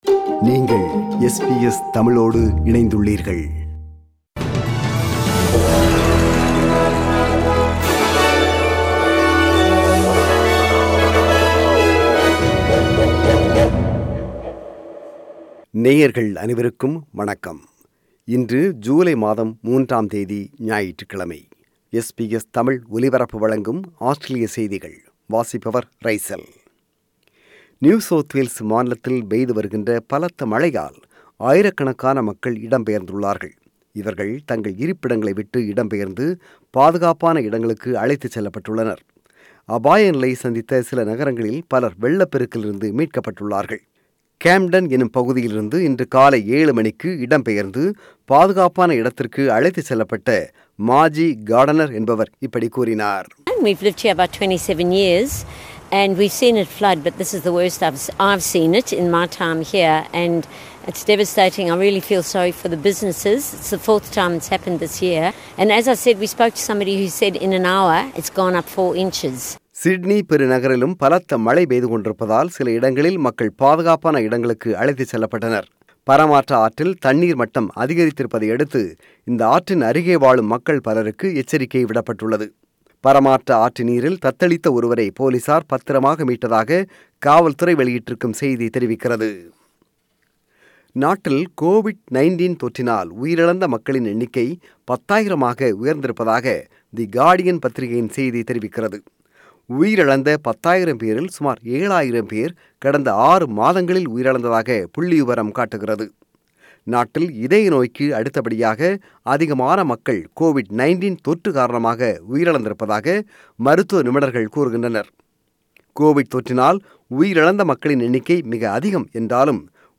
Australian News: 3 July 2022 – Sunday